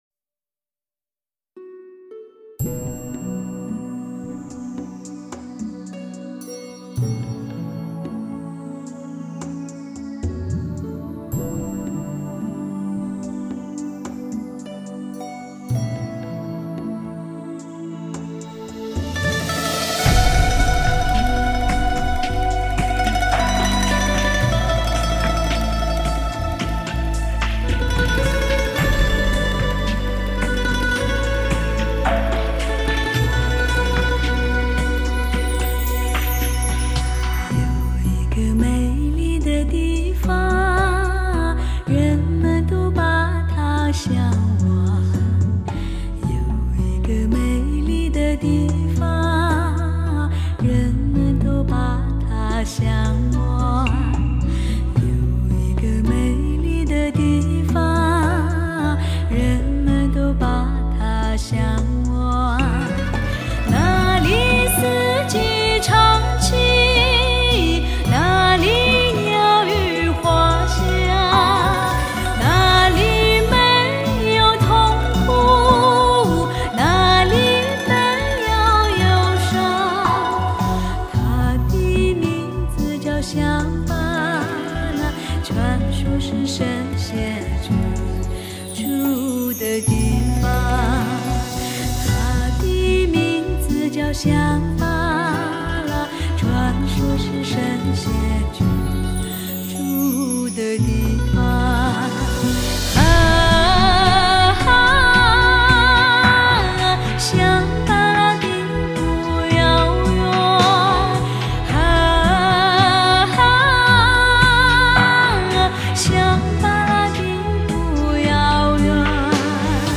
本专辑立足中国本土民歌，融入NEW WORLD的音乐元素，打造显著的新民歌风尚，开创现代民歌典范。
野性而温暖、高亢而含蓄的歌声，